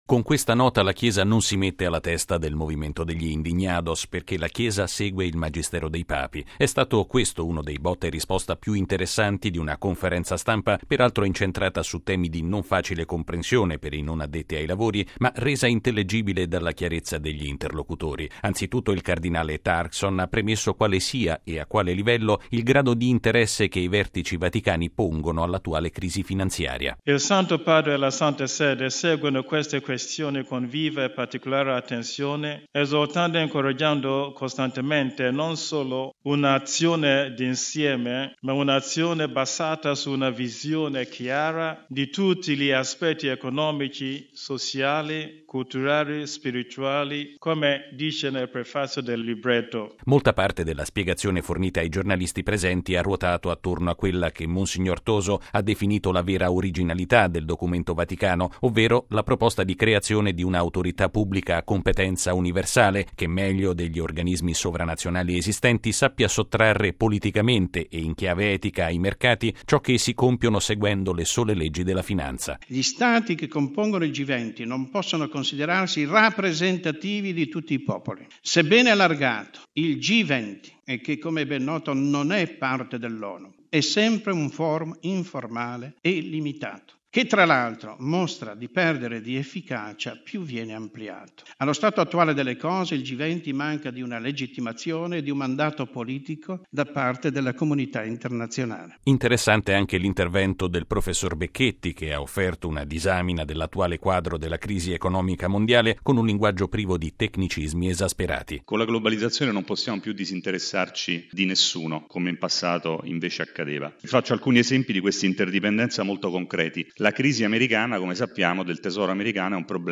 ◊   Con il documento presentato oggi dal Pontificio Consiglio Giustizia e Pace, si ribadiscono alcuni punti di un magistero pontificio che viene da lontano: il mondo della finanza non può schiacciare i bisogni dell’umanità e ciò che mostra di non essere legittimato o rappresentativo di tutti i popoli deve poter essere riformato. Sono alcuni dei concetti emersi questa mattina nella conferenza stampa di presentazione della Nota del dicastero pontificio.